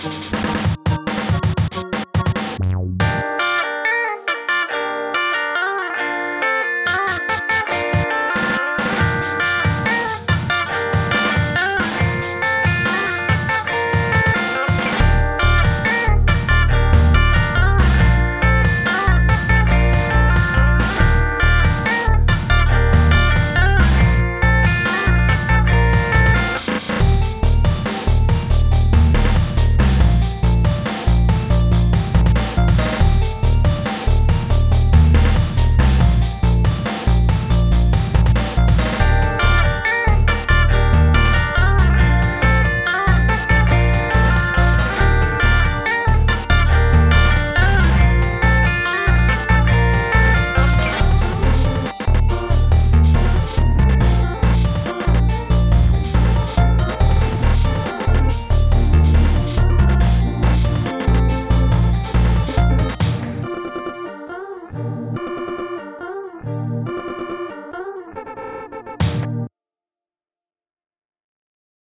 tapping interlude 01.wav